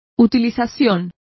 Complete with pronunciation of the translation of utilization.